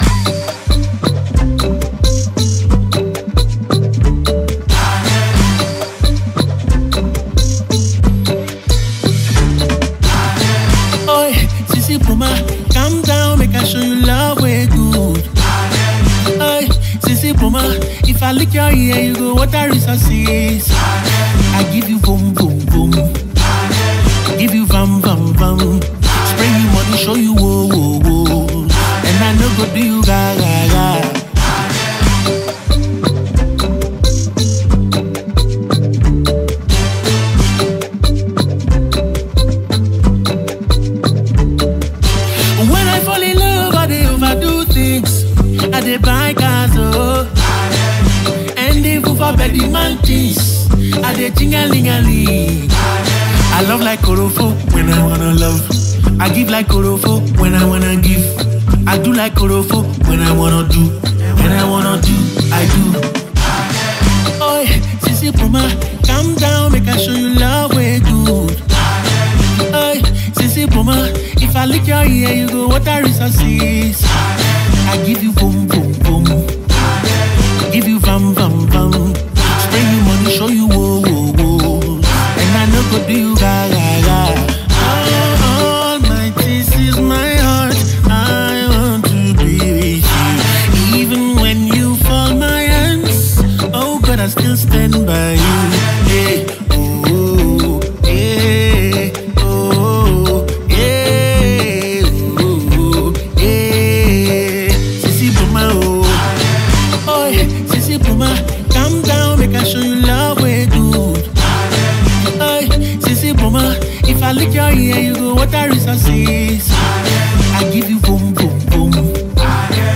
fresh upbeat Gyration-themed love bang.